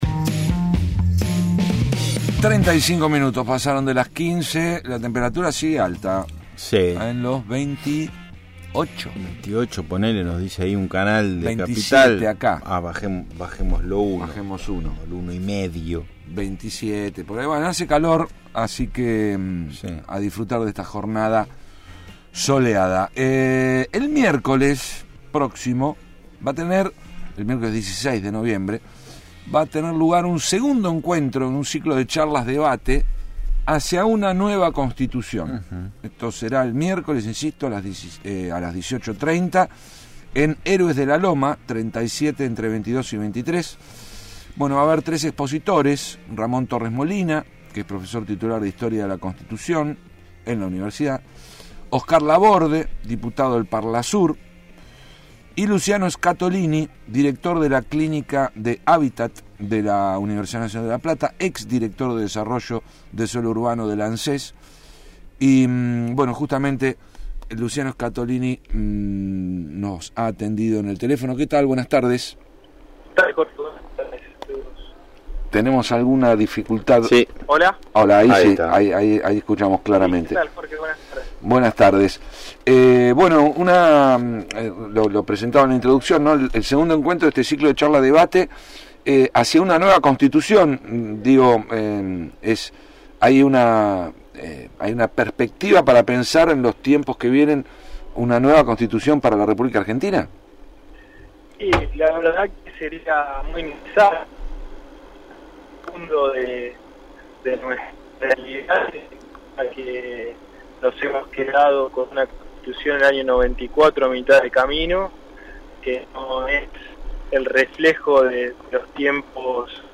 Charla sobre derecho a la vivienda – Radio Universidad